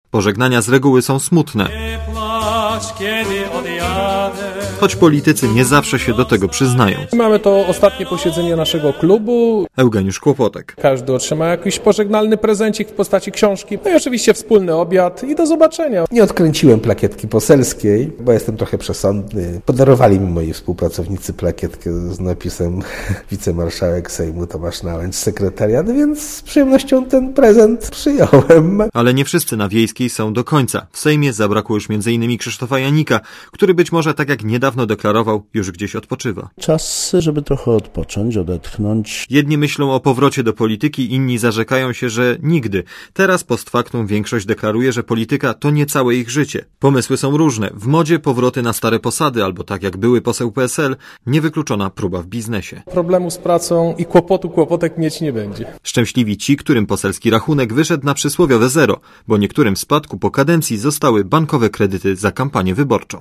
Jak wyglądał ich ostatni dzień w Sejmie? Źródło: PAP Relacja reportera Radia ZET Oceń jakość naszego artykułu: Twoja opinia pozwala nam tworzyć lepsze treści.